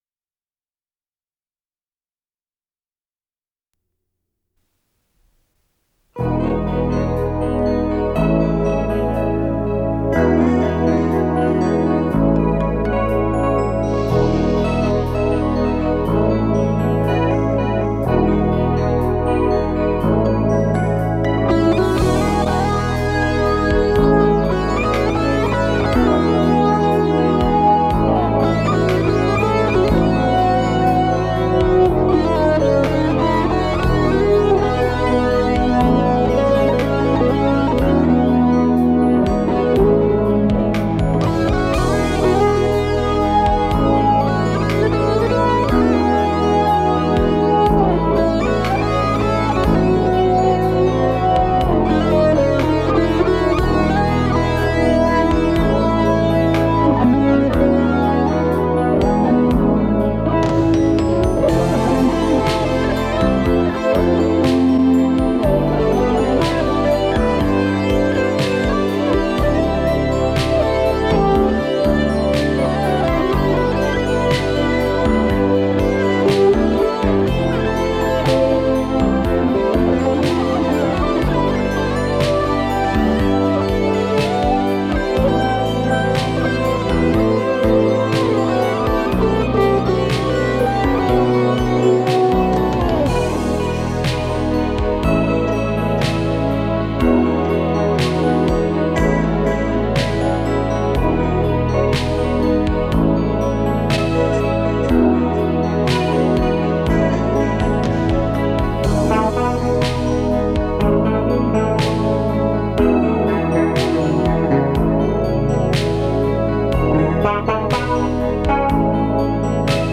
с профессиональной магнитной ленты
ПодзаголовокИнструментальная пьеса
ВариантДубль моно